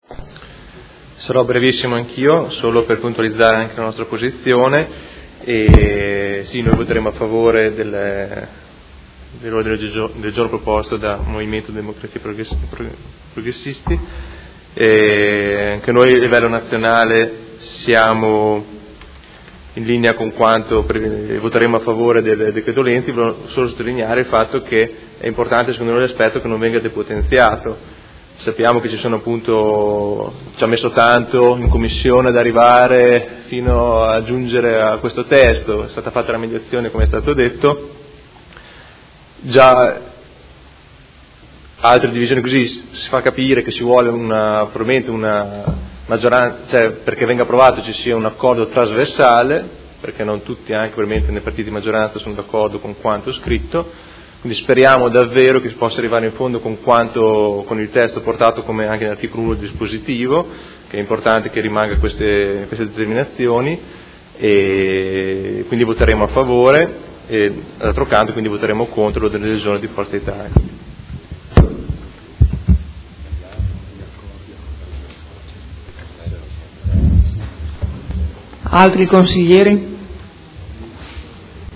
Marco Rabboni — Sito Audio Consiglio Comunale